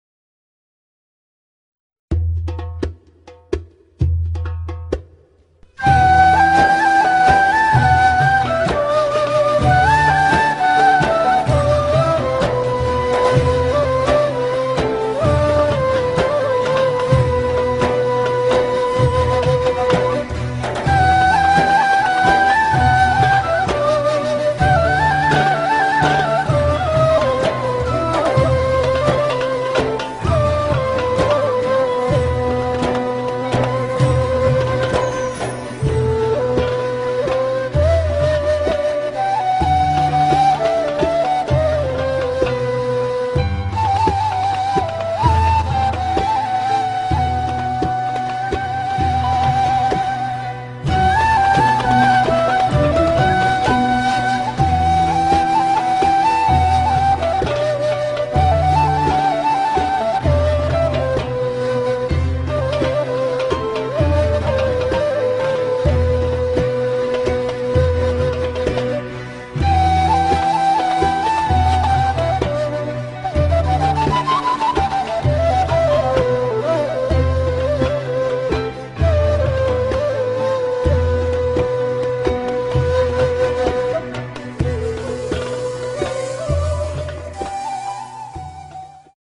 2 – Música d’Art – Tûrk sanat müzigi
És la música tradicional Otomana i el repertori que utilitza l’ordre dels medleví
tc3bcrk-sanat.mp3